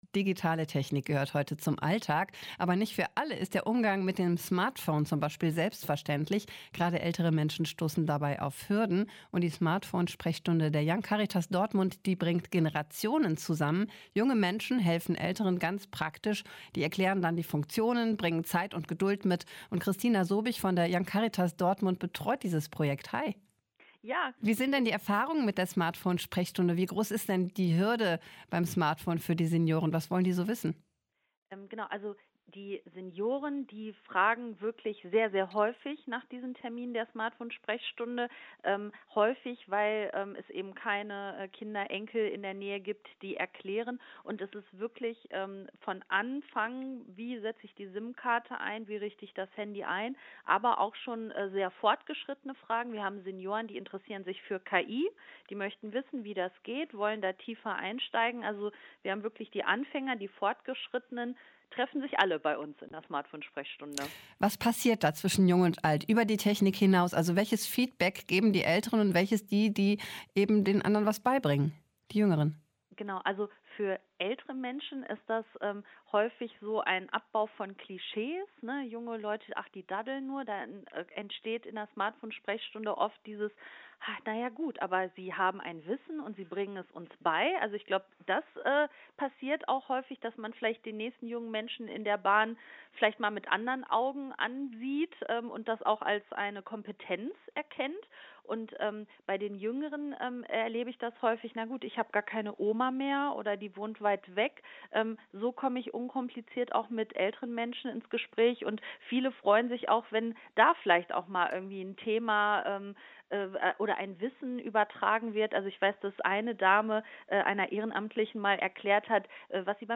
In der Smartphone-Sprechstunde der Young-Caritas Dortmund helfen junge Ehrenamtliche und schaffen so ein generationenübergreifendes Miteinander. Ein Interview